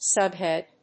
súb・hèad
音節sub･head発音記号・読み方sʌ́bhèd